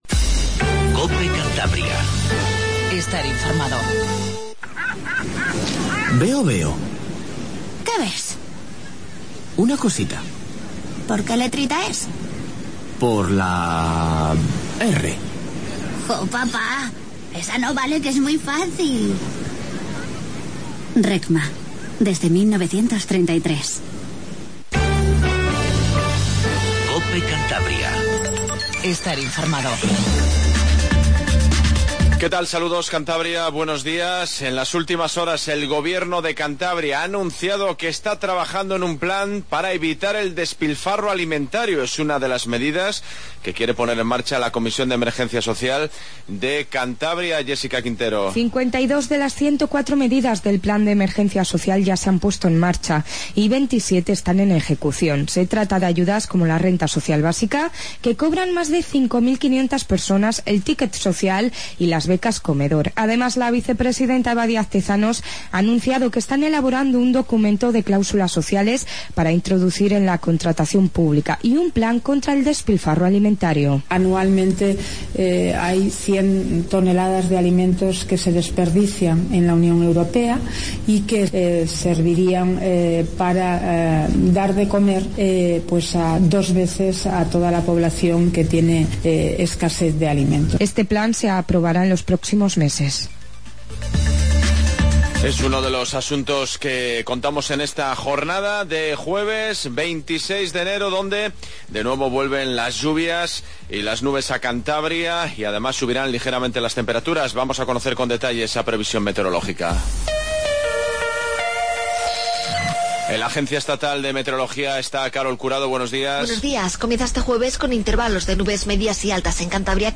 INFORMATIVO MATINAL 08:20